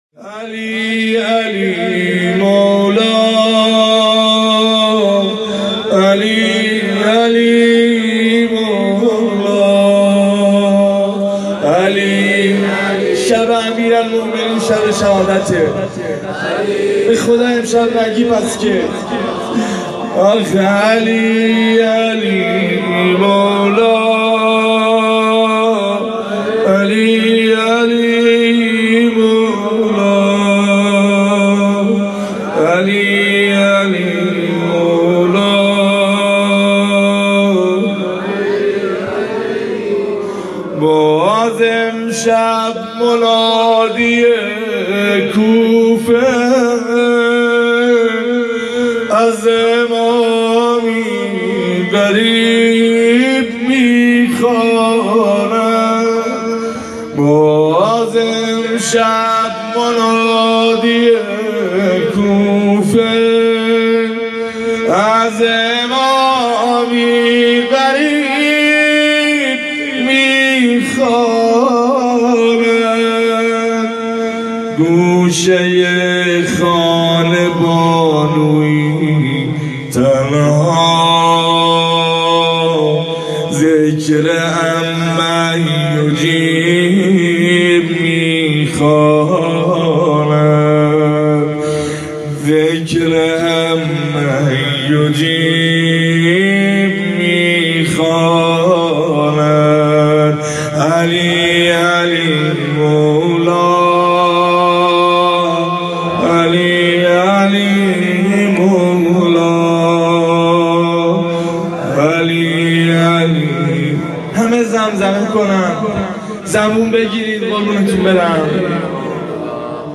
روضه شب شهادت امیرالمومنین(ع)